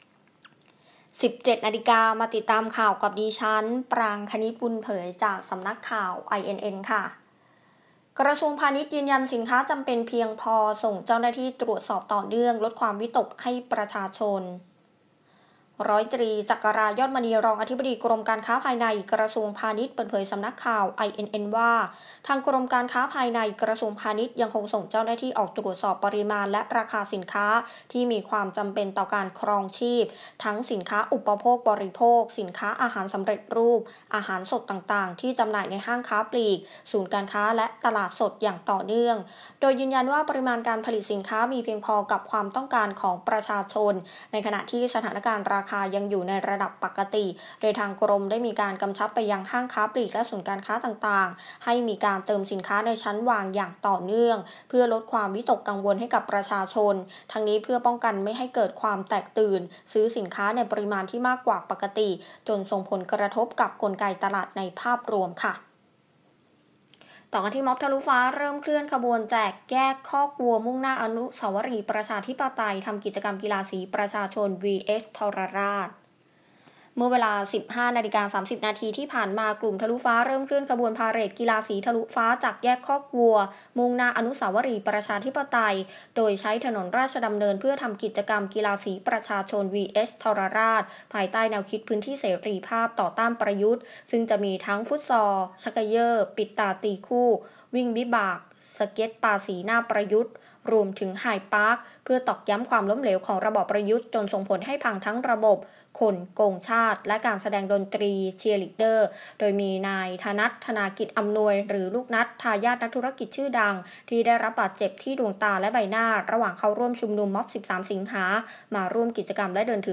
ข่าวต้นชั่วโมง 17.00 น.